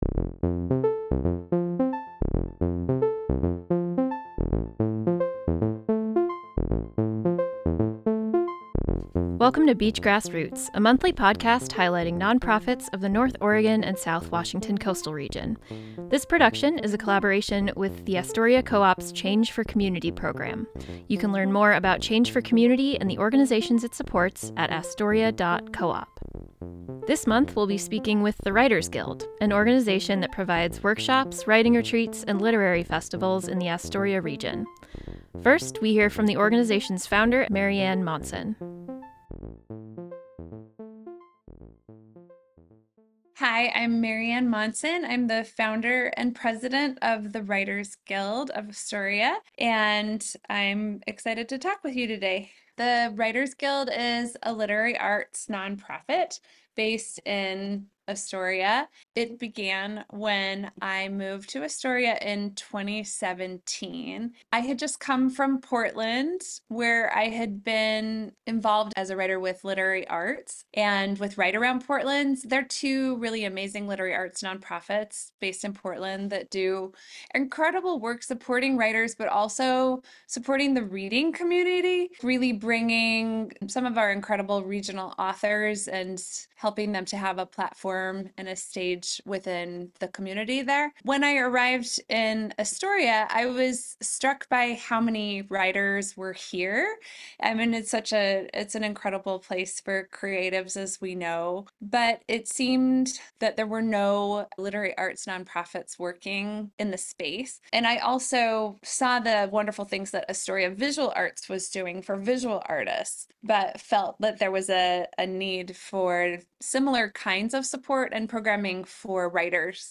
In this episode of Beach Grass Roots, we talk with members of the Writers Guild.